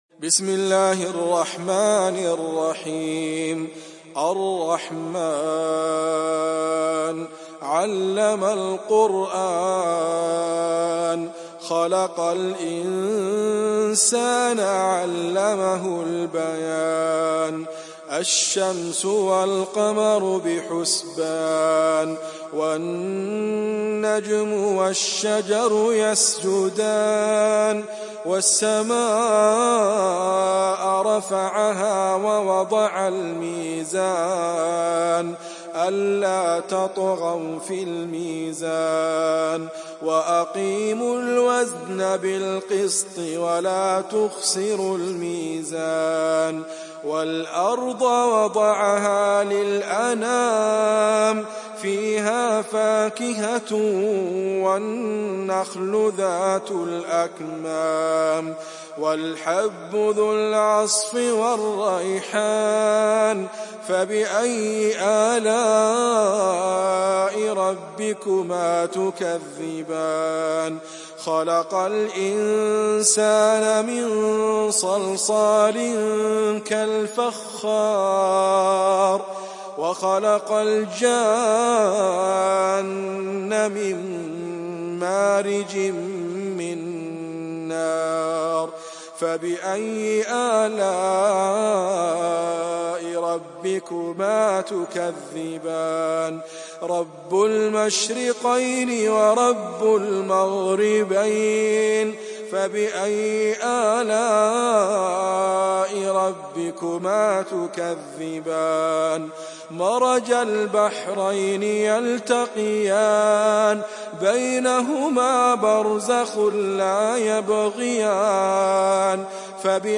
تحميل سورة الرحمن mp3 بصوت إدريس أبكر برواية حفص عن عاصم, تحميل استماع القرآن الكريم على الجوال mp3 كاملا بروابط مباشرة وسريعة